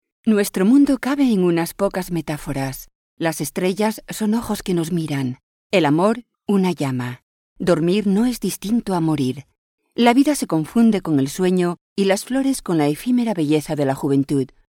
Sprecherin spanisch. Werbesprecherin. Sprecherin für Sprachkurse.
kastilisch
Sprechprobe: Industrie (Muttersprache):
Spanish female voice over talent.